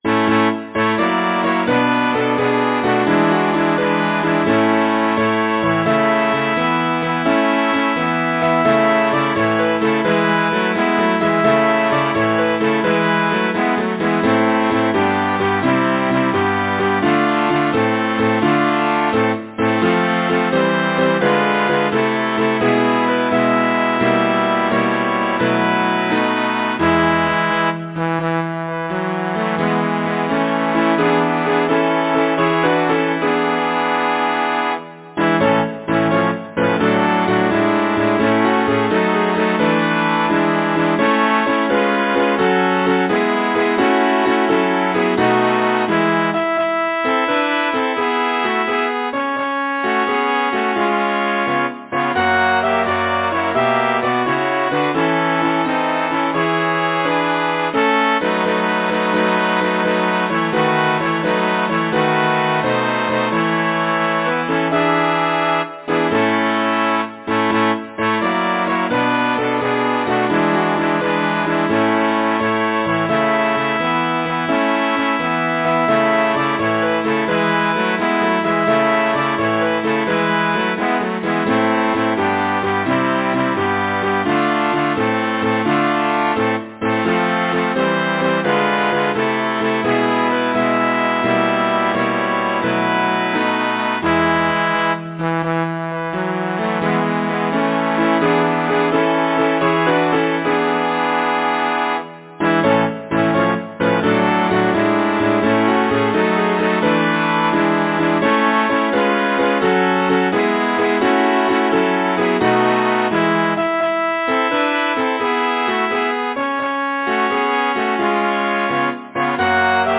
Number of voices: 4vv Voicing: SATB Genre: Secular, Partsong
Language: English Instruments: Piano